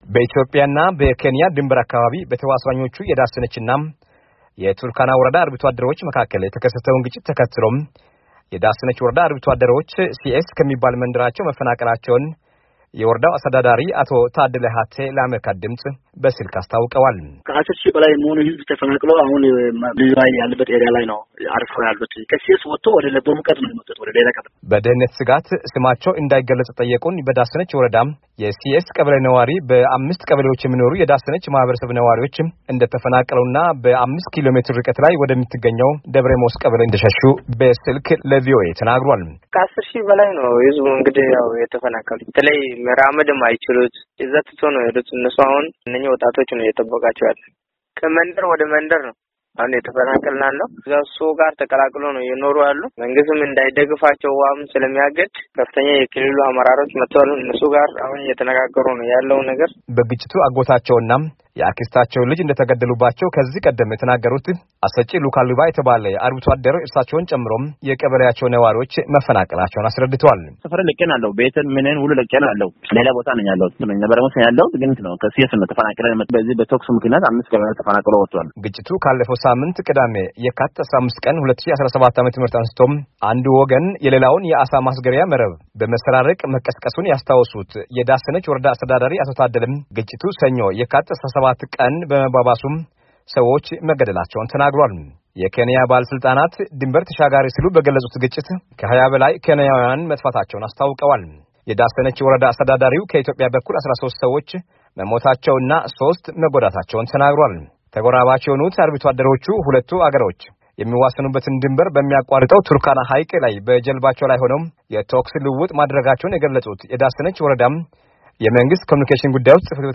በኢትዮጵያ እና በኬንያ ድንበር አካባቢ በተዋሳኞቹ የዳሰነች ወረዳ እና የቱርካና ወረዳ አርብቶ ዐደሮች መካከል የተከሠተውን ግጭት ተከትሎ፣ የዳሰነች ወረዳ አርብቶ አደሮች ሲአስ ከሚባል መንደራቸው መፈናቀላቸውን፣ የወረዳው አስተዳደሪ አቶ ታደለ ሀቴ ለአሜሪካ ደምፅ በስልክ አስታውቀዋል። በደኅንነት ሰጋት ስማቸው እንዳይገለጽ የጠየቁን በዳሰነች ወረዳ የሲአስ ቀበሌ ነዋሪ፣ በስምንት ቀበሌዎች የሚኖሩ የዳሰነች ማኅበረሰብ ነዋሪዎች እንደተፈናቀሉና በአምስት ኪሎ ሜትር...